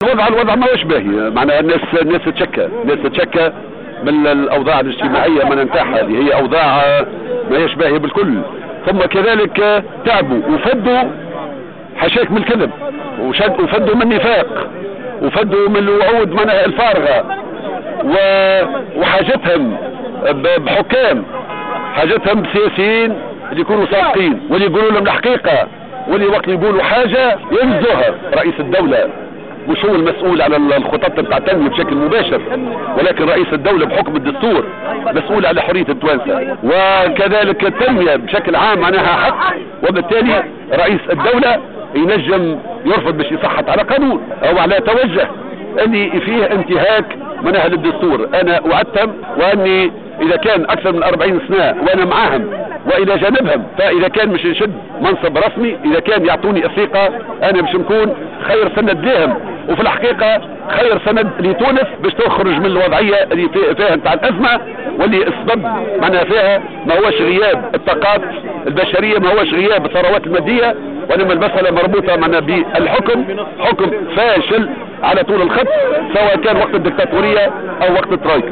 قال المترشح للإنتخابات الرئاسية حمة الهمامي على هامش زيارته لولاية المهدية في اطار حملته الإنتخابية اليوم الخميس أن التونسيين في حاجة اليوم لحكام وسياسيين صادقين وقادرين على الإيفاء بوعودهم.